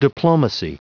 Prononciation du mot diplomacy en anglais (fichier audio)
diplomacy.wav